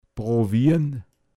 Pinzgauer Mundart Lexikon
Details zum Wort: prowian. Mundart Begriff für probieren